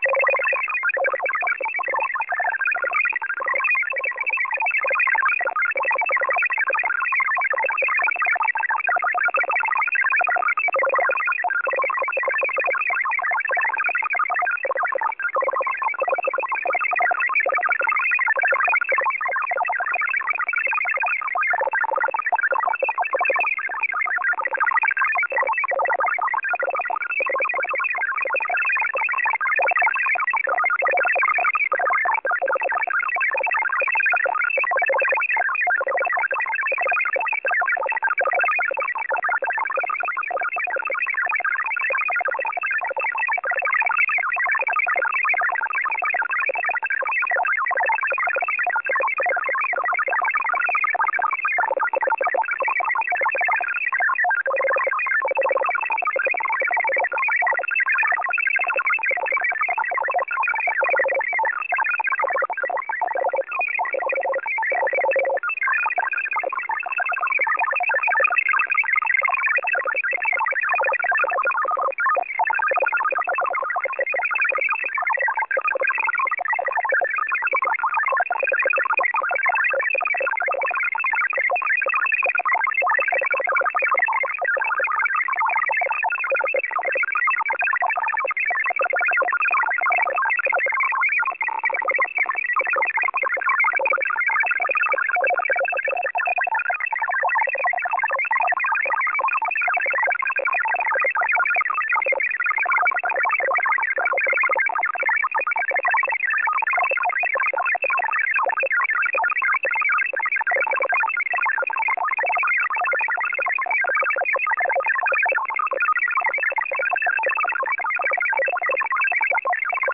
Time (UTC): 19:50 UTC Mode: USB Frequency: 8147.00kHz Recording: websdr_recording_start_2019-04-30T19_50_04Z_8147.0kHz.wav Waterfall Image: Screenshot 2019-04-30 at 3.50.46 PM.png Date (mm/dd/yy): 4/30/19